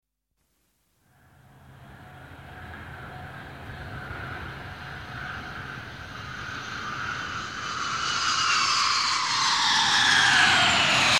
دانلود آهنگ سه بعدی 11 از افکت صوتی طبیعت و محیط
جلوه های صوتی